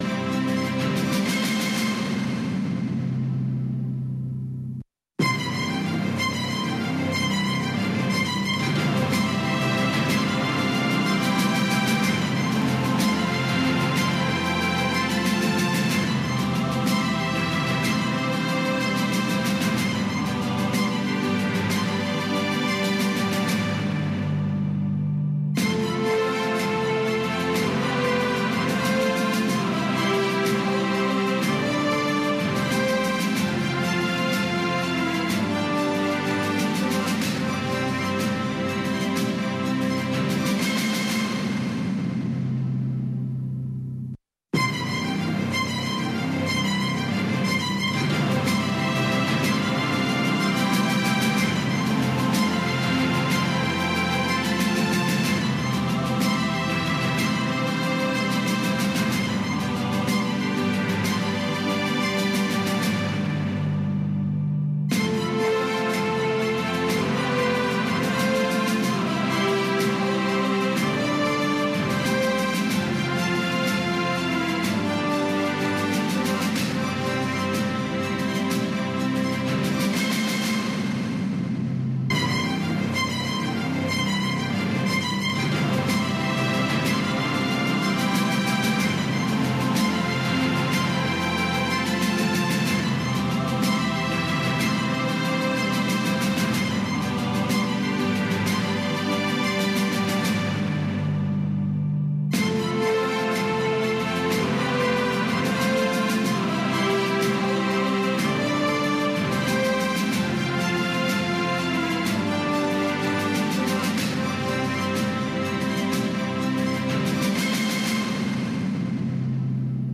خبرونه
د وی او اې ډيوه راډيو ماښامنۍ خبرونه چالان کړئ اؤ د ورځې د مهمو تازه خبرونو سرليکونه واورئ.